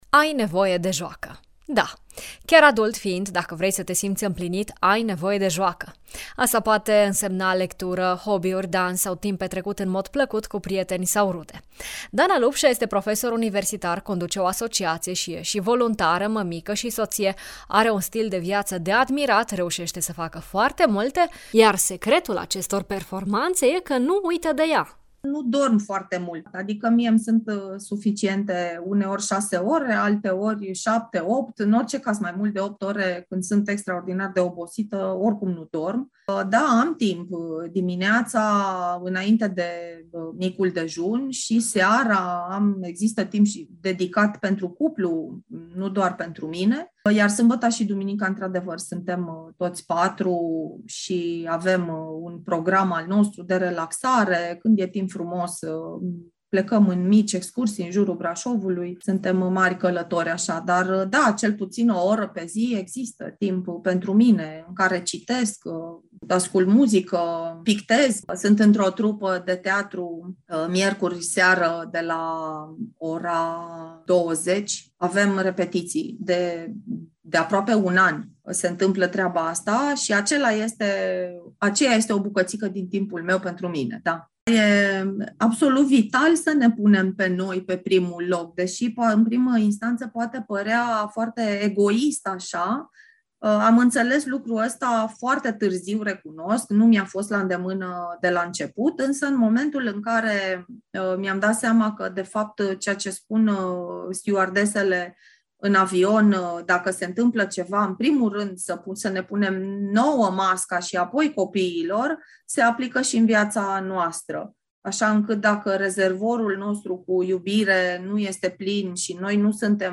prof. universitar